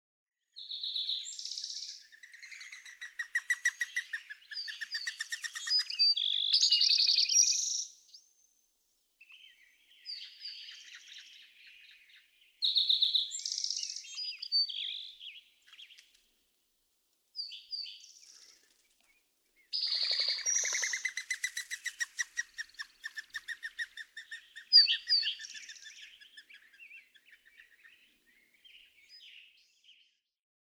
Mourning dove
Subchapter: Mechanical (non-vocal) sounds
♫63. Whistling wings on landing and takeoff, accompanied by relatively loud songs from a spotted towhee (e.g., at 0:06, 0:12, etc.); these are the "mountain" spotted towhees, who have more introductory notes to their songs than do the spotted towhees nearer the Pacific Ocean (e.g., ♫257, ♫423).
Temple Canyon Park, Cañon City, Colorado.
063_Mourning_Dove.mp3